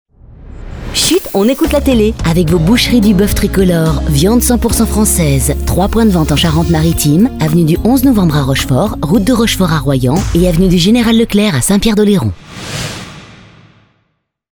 et voici le spot de notre partenaire